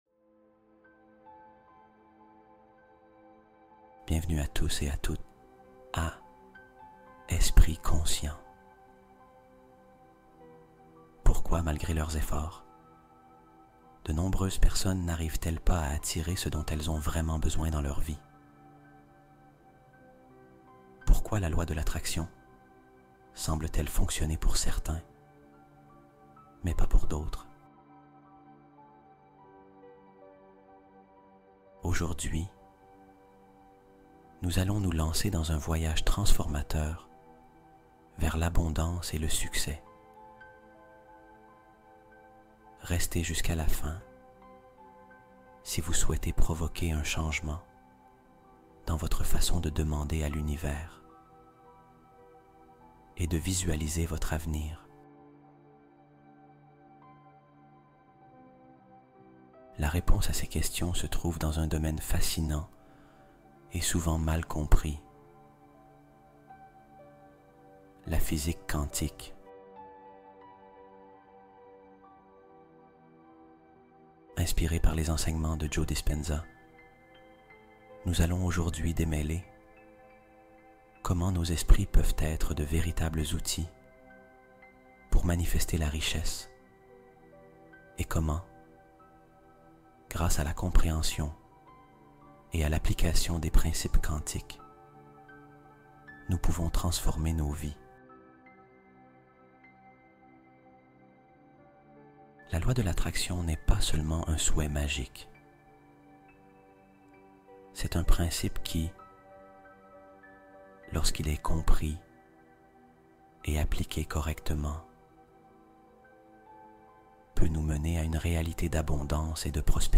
Hypnose du soir apaisante : consolider un esprit stable et serein